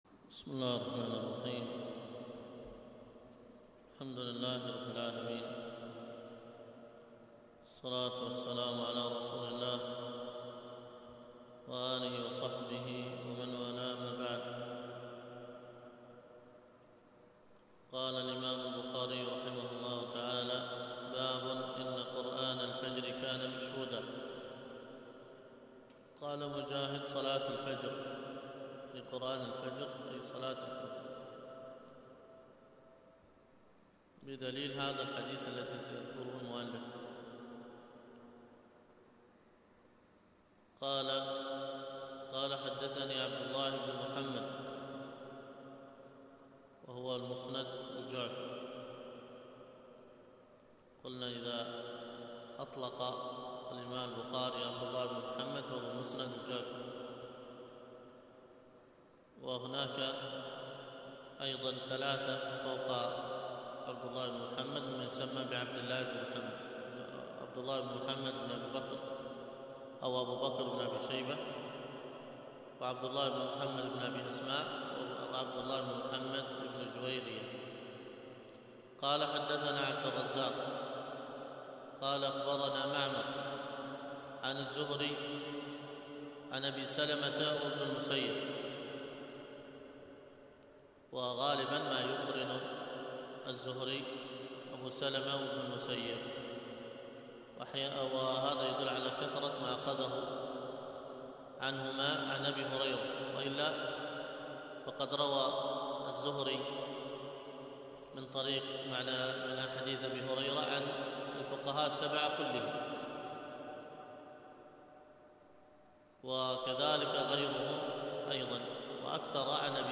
الدرس في شرح كتاب التوحيد مختصر 4، الدرس الرابع:من